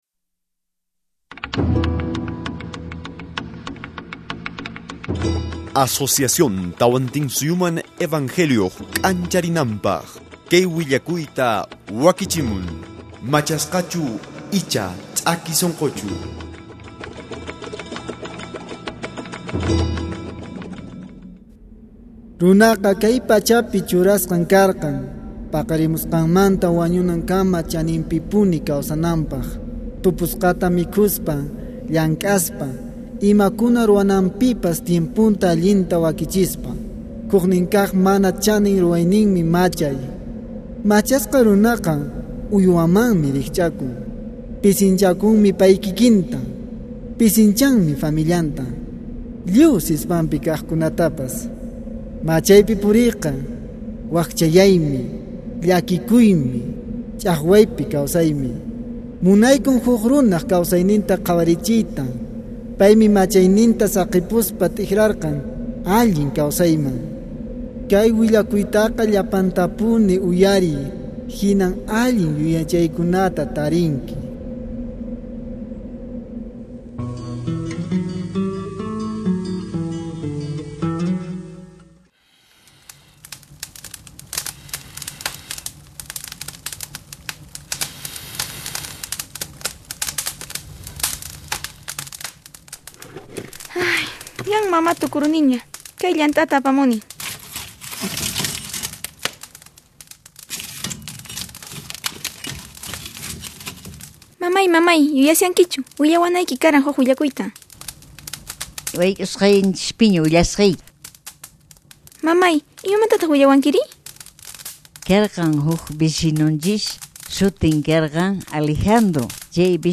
Series of 5 Dramatized Audio Testimonials, covering 5 very common social problems.